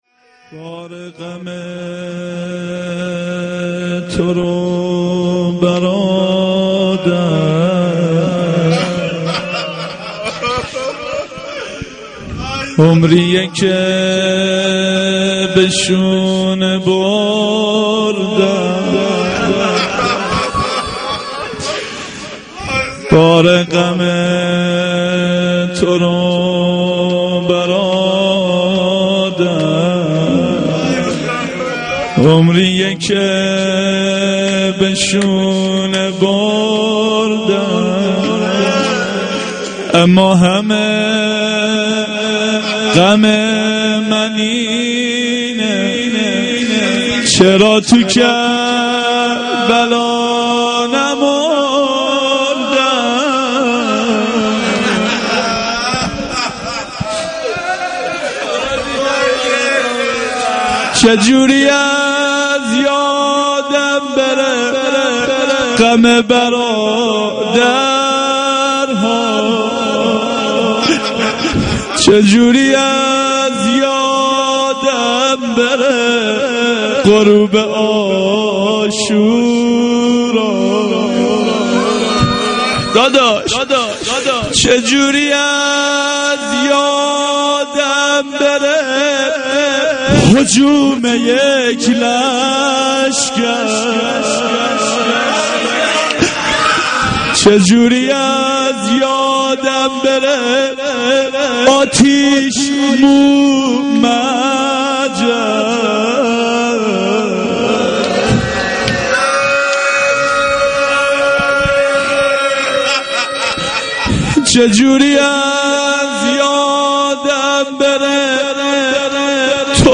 shahadate-h.-zeynab-s-93-rozeh.mp3